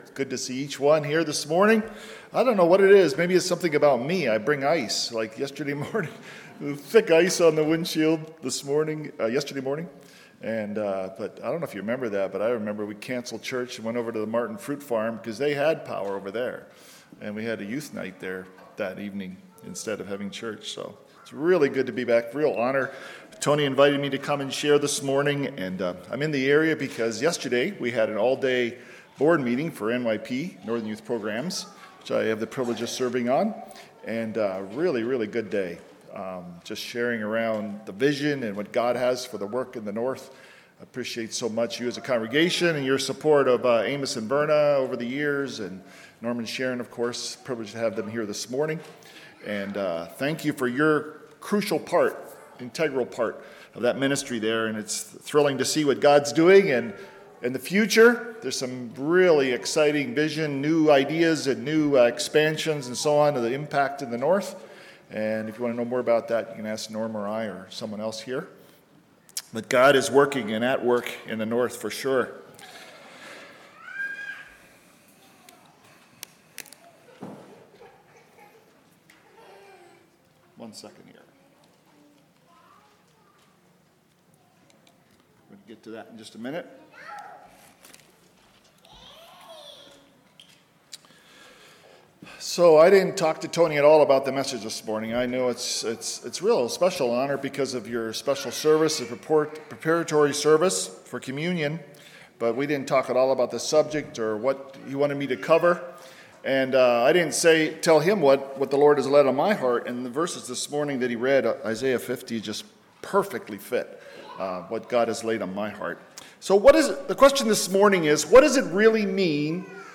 Sermons/Media - Faith Mennonite Church